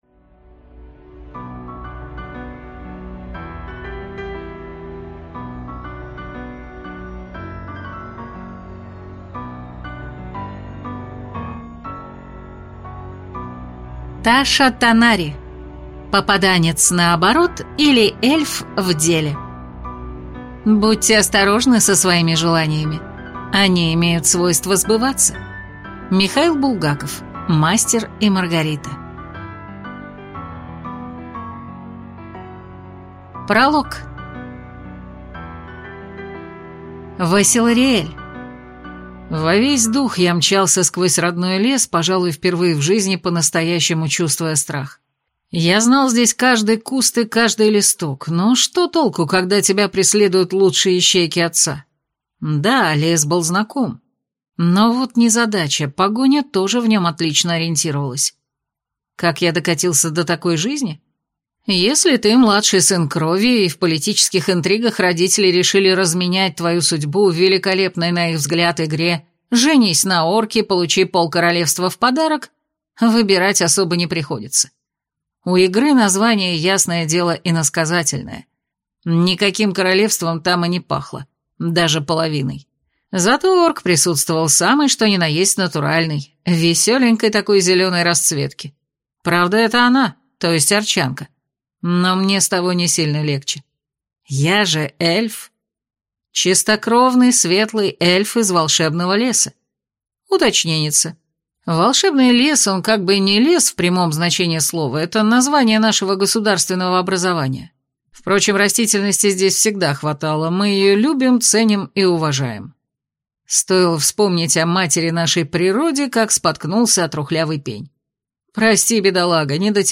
Аудиокнига Попаданец наоборот, или Эльф в деле | Библиотека аудиокниг